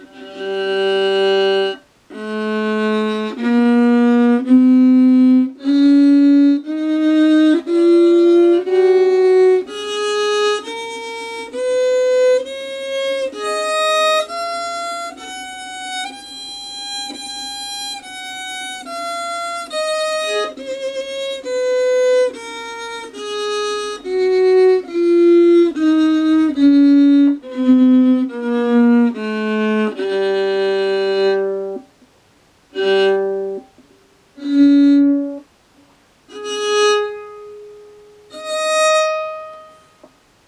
New Bridge
You have clean bowing and a nice pure sound.